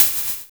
1714L OHH.wav